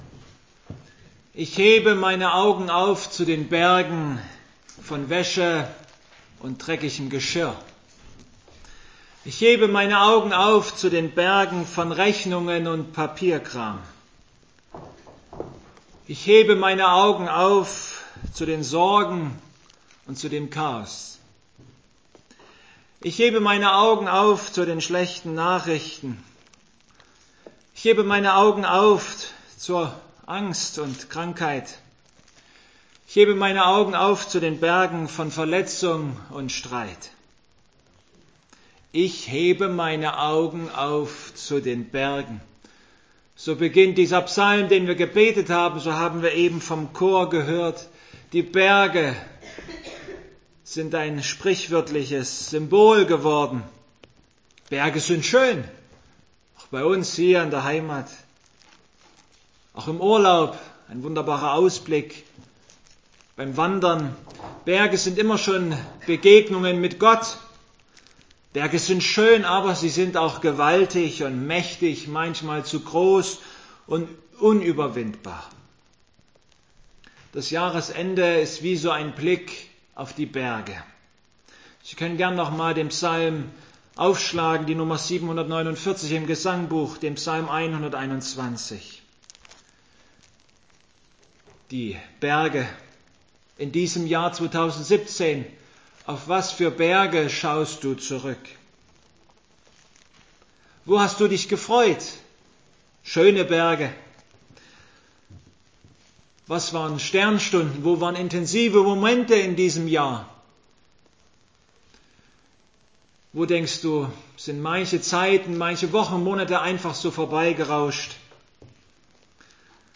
Passage: Psalm 121 Gottesdienstart: Abendmahlsgottesdienst Heute ist der erste Sonntag nach dem Christfest